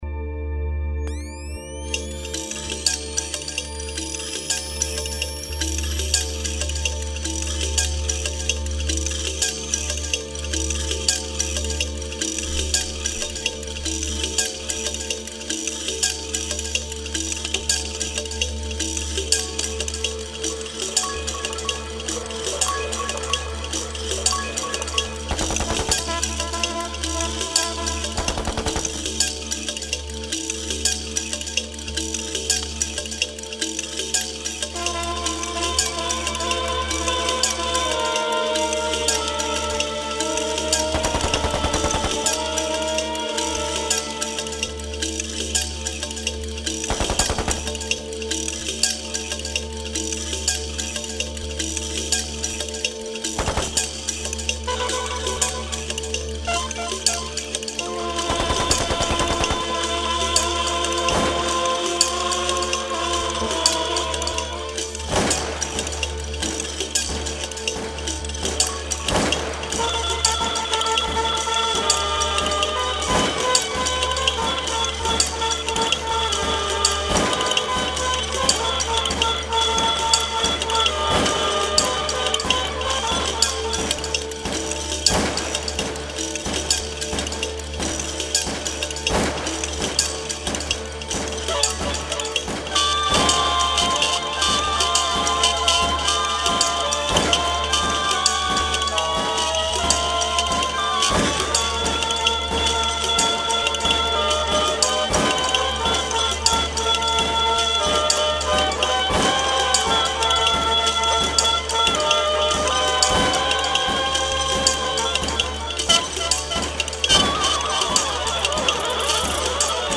with haunting rhythms that go as far as trance
UK-Drill